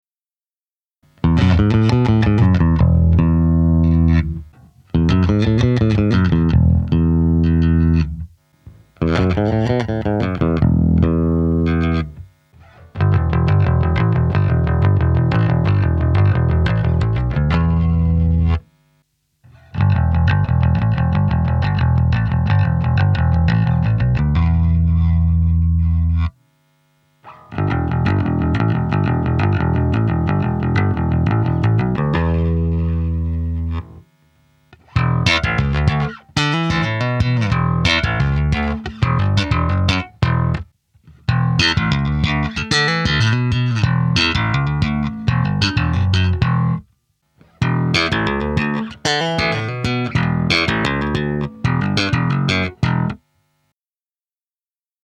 swing_bass.mp3